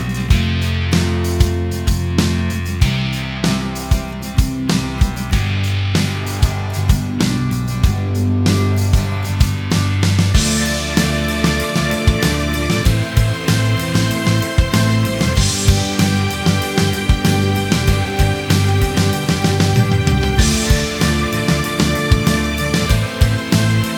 Minus All Guitars Indie / Alternative 4:47 Buy £1.50